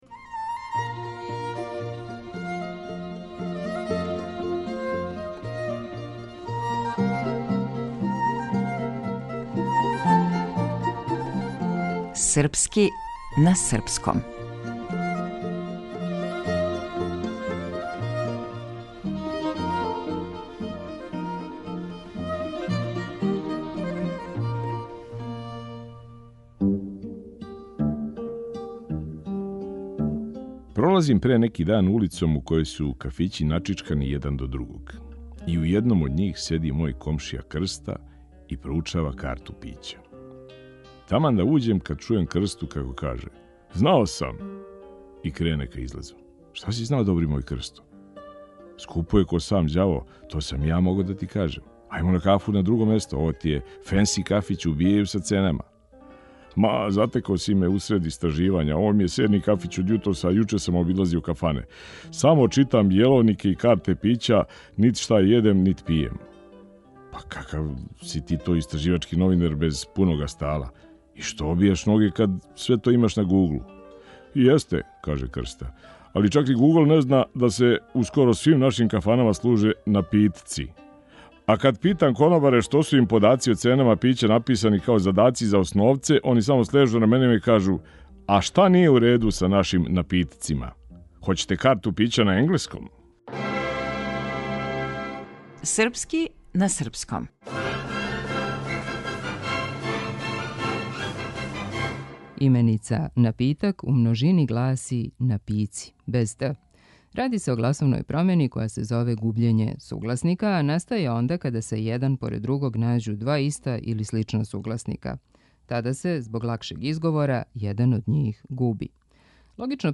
Глумац - Никола Којо